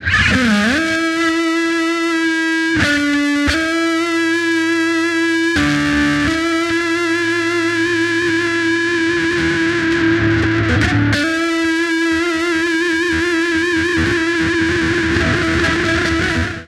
Track 02 - Guitar Lead 05.wav